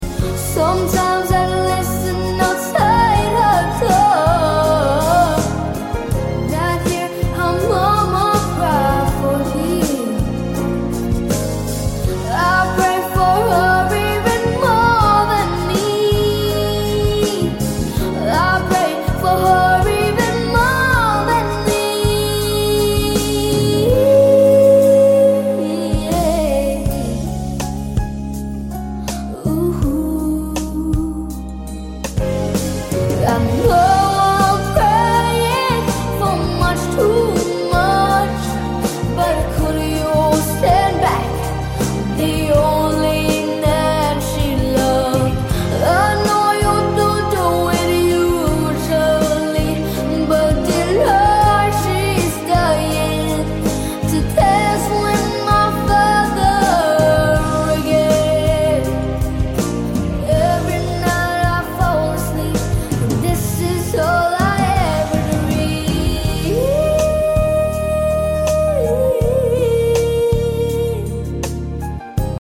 (Cover)